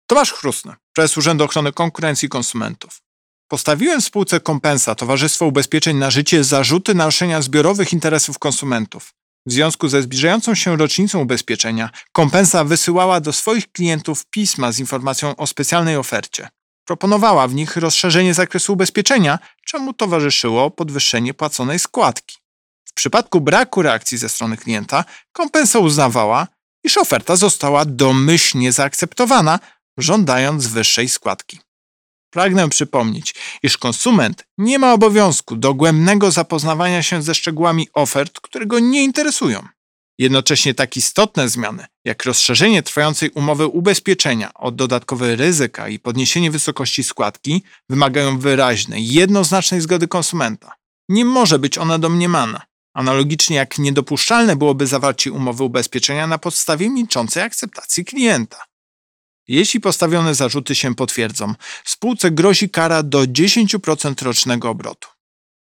Wypowiedź Prezesa UOKiK Tomasza Chróstnego z 17 maja 2021 r..mp3